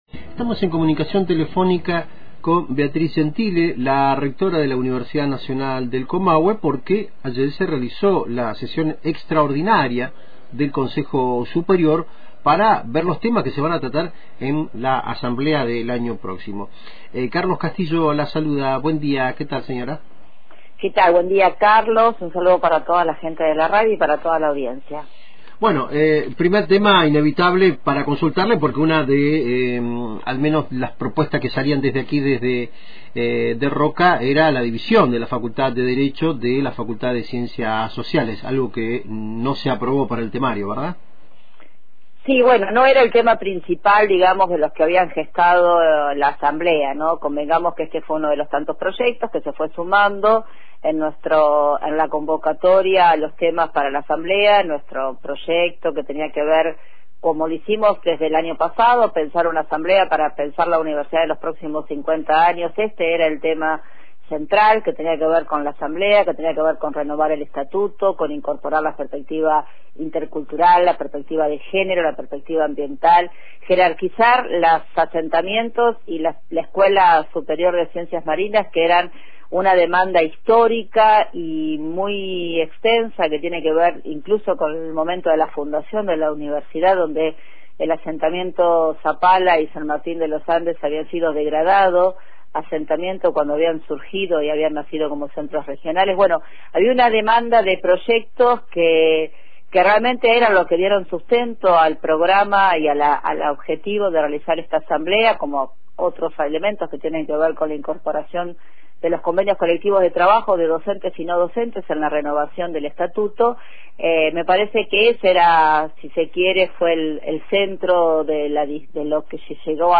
Tras la resolución de no tratar la división de la Facultad de Derecho y Ciencias Sociales en la sesión extraordinaria del Consejo Superior universitario, conversamos con la rectora Beatriz Gentile.